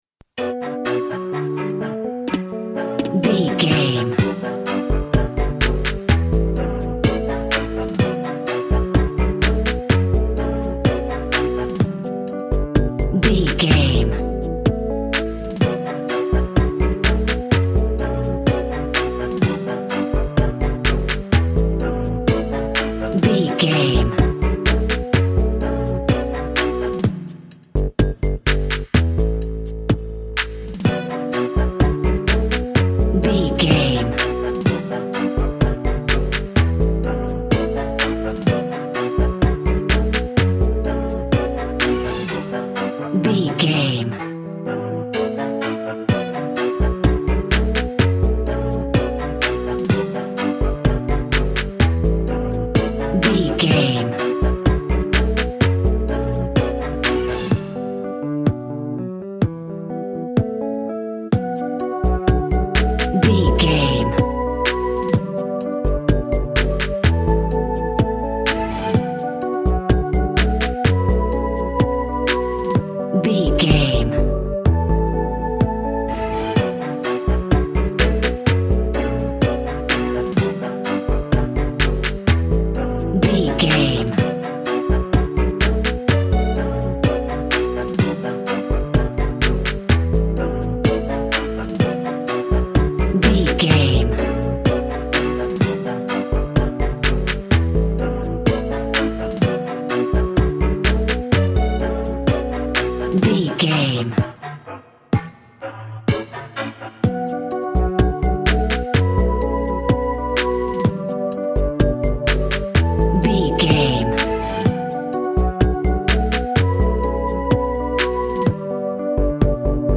Also with small elements of Dub and Rasta music.
Aeolian/Minor
tropical
reggae
drums
bass
guitar
piano
brass
pan pipes
steel drum